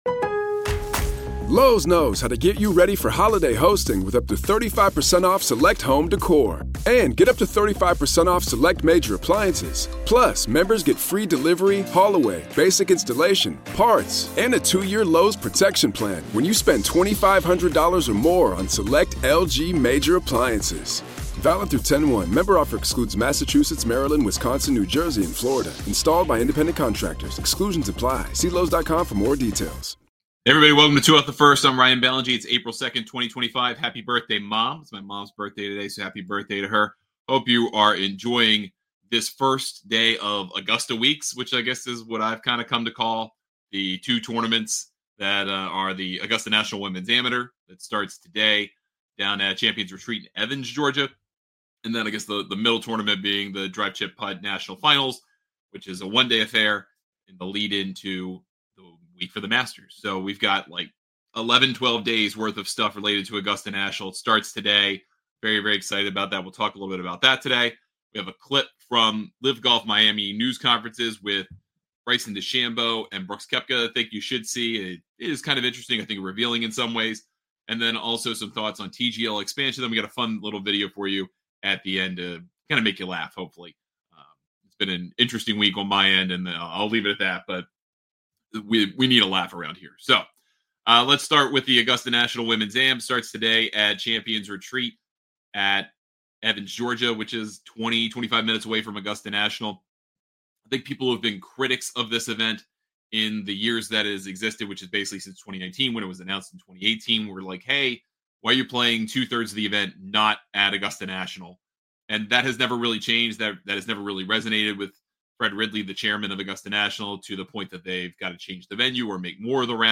On today's LIVE show